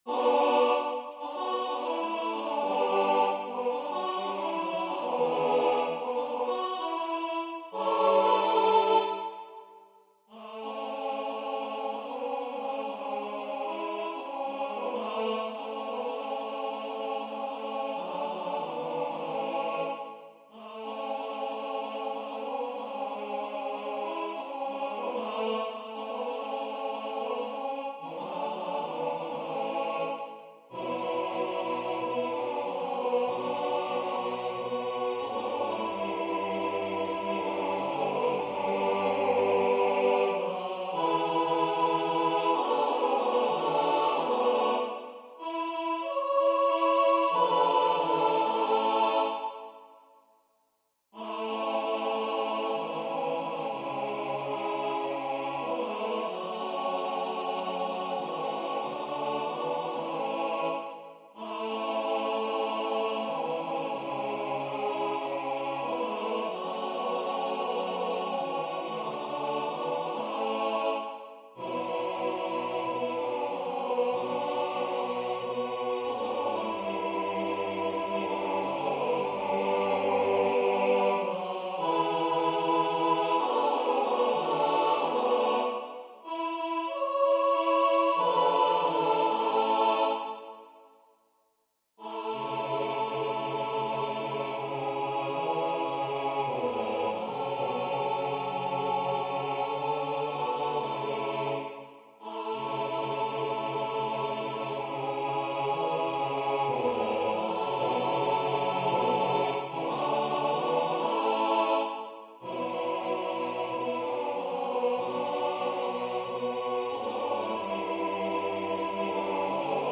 Genre : pop
anglais Voix : SATB Difficulté